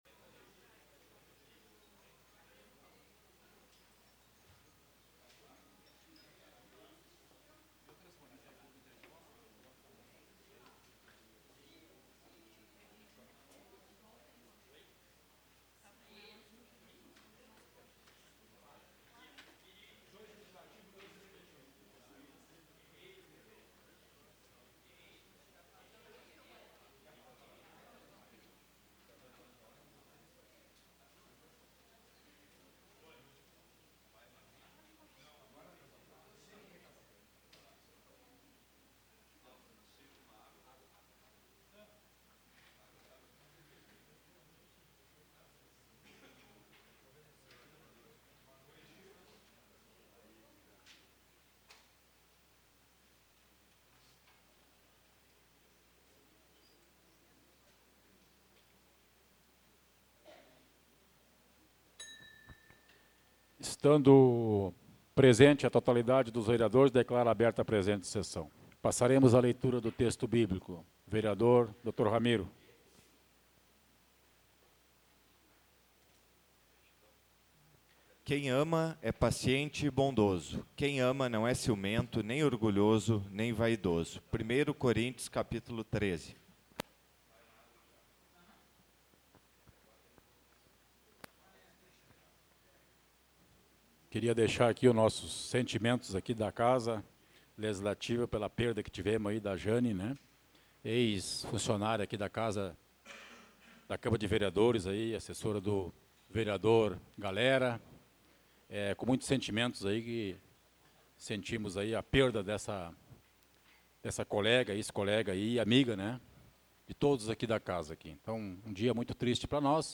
Áudio Sessão 12.06.2023 — Câmara de Vereadores